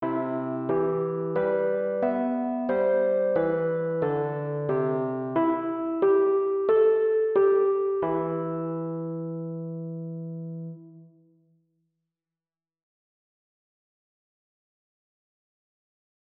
[NOTE: The multivoice strategy actually plays the notes, so if you want to hear it, this is the best option, but it does no harm to add a text remark as an additional cue.
-- Electric Piano -- WAV Audio File